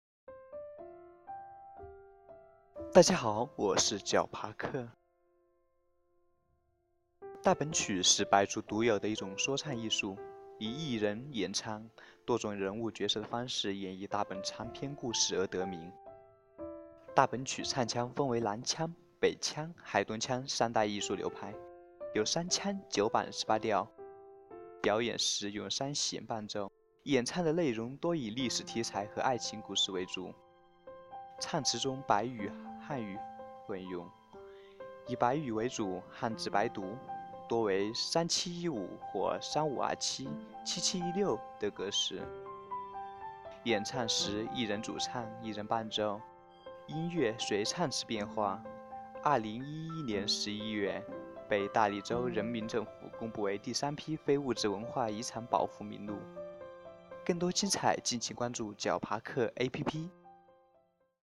大本曲是白族独有的一种说唱艺术，以一人演唱多种人物角色的方式演绎大本长篇故事而得名。大本曲唱腔分为南腔、北腔、海东腔三大艺术流派，有三腔、九板、十八调。表演时用三弦伴奏，演唱的内容多以历史题材和爱情生活为主。
演唱时，一人主唱，一人伴奏，音乐随唱词变化。